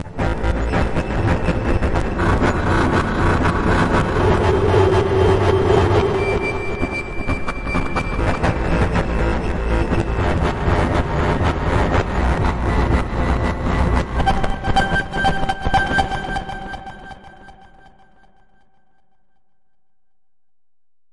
龙卷风内部
描述：这是一种非常强烈的龙卷风声。
标签： 极端 强烈的 白色的 扭曲的 实验性 低音 黑暗 扭曲 旋风 SFX 反馈 噪音
声道立体声